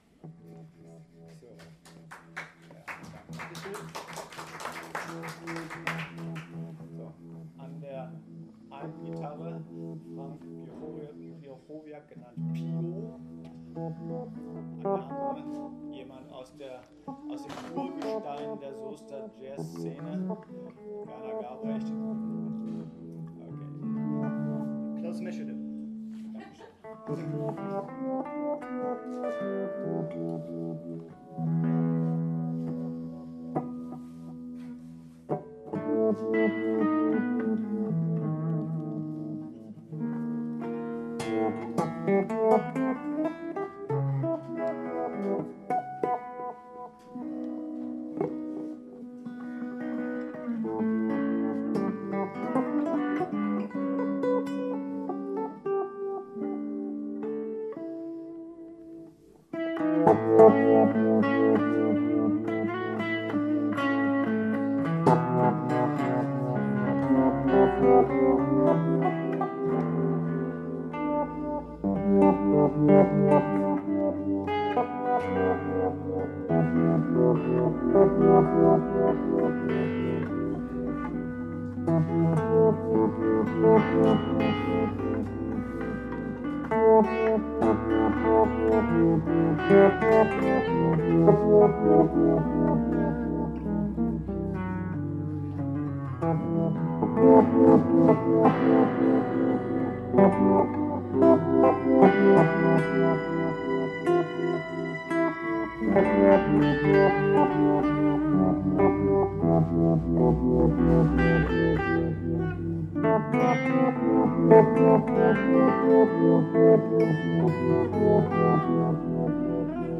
Saxophon, Elektronik
E-Gitarre
Gitarren live bei der Jazznacht Soest am 15.02.2025 Dieses Stück ist der Opener auf der Basis des Folk-Klassikers Greensleaves.